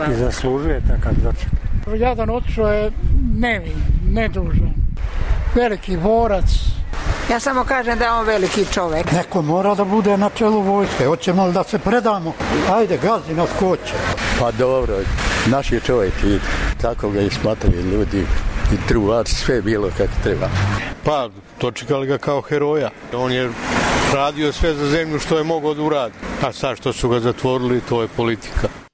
Beograđani o dočeku haških osuđenika